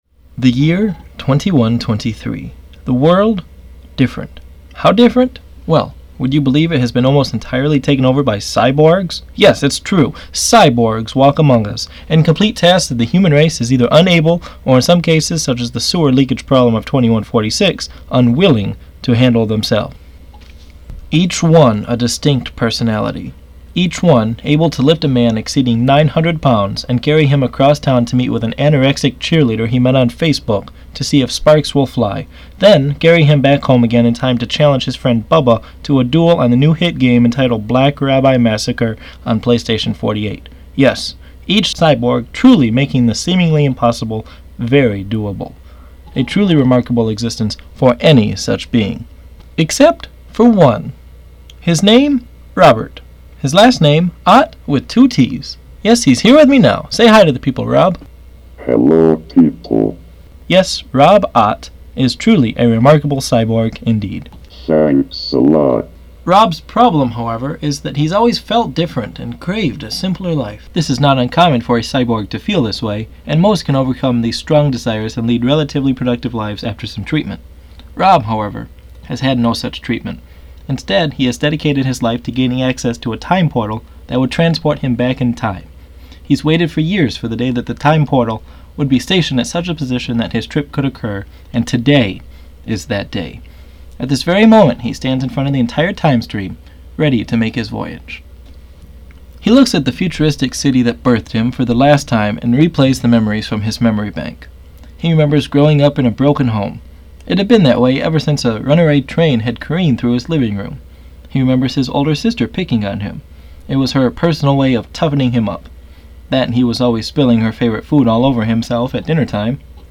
Rob & Hez is an upcoming audio show brought to you by Wayward Orange.